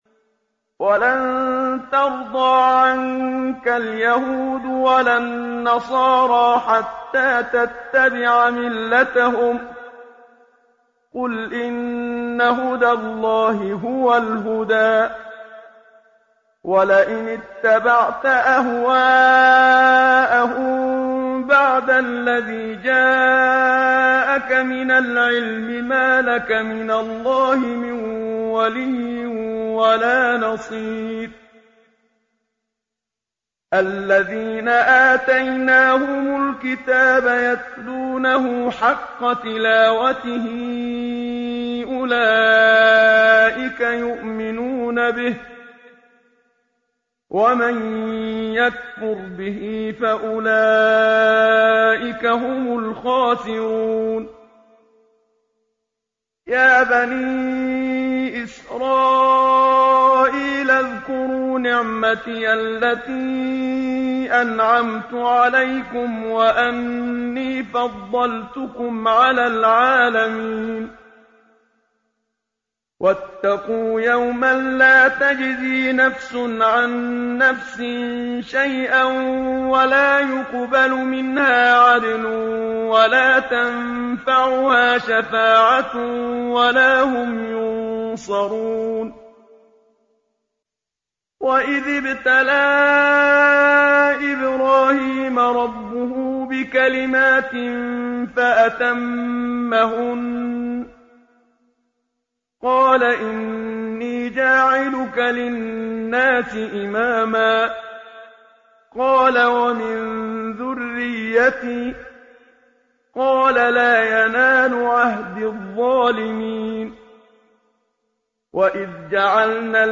تلاوت روزانه قرآن کریم - مؤسسه راهیان کوثر غدیر یزد